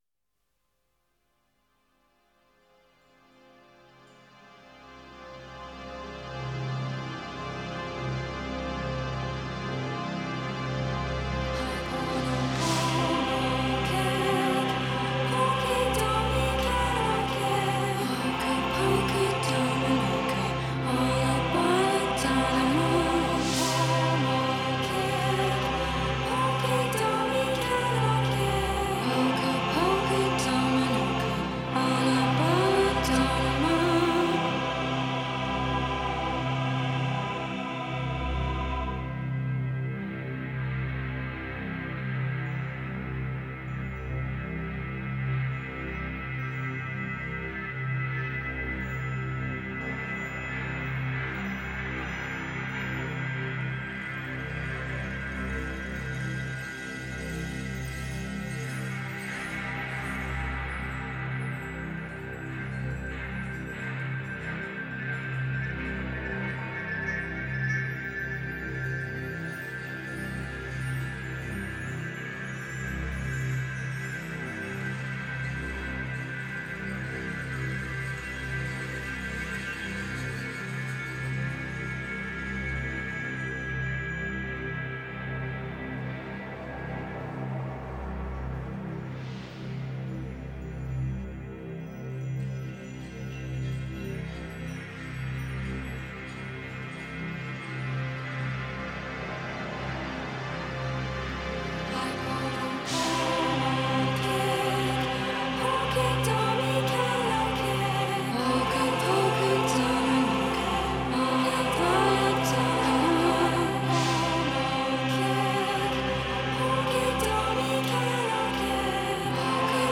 Ethereal